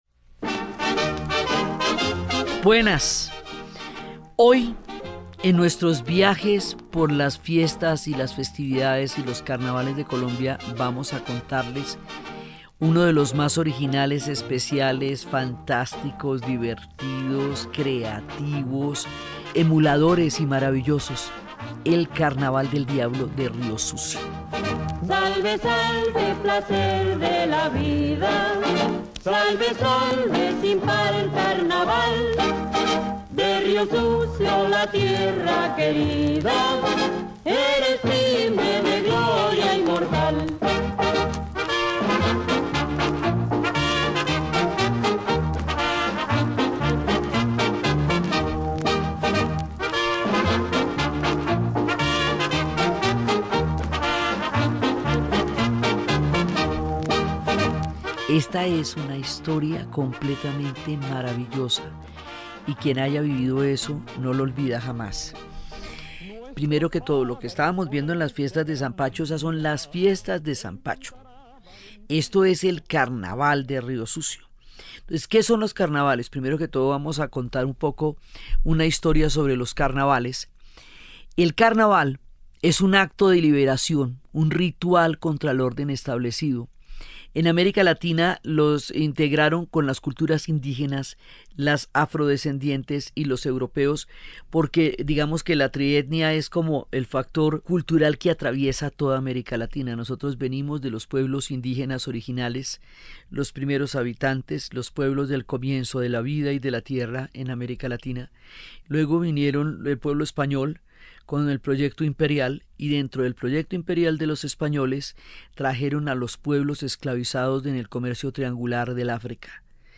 Conoce la historia de cómo se originó el Carnaval de Riosucio, Caldas en la voz de Diana Uribe ingresando gratis a la plataforma de RTVCPlay.